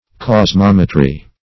Meaning of cosmometry. cosmometry synonyms, pronunciation, spelling and more from Free Dictionary.
cosmometry.mp3